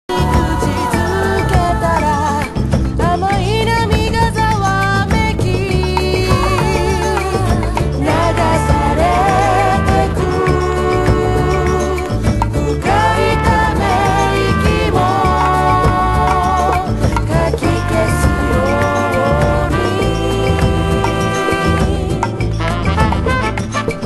HOUSE MUSIC
○屋外/サンセットにマッチしそうな、ピースフルなオーガニックトラック！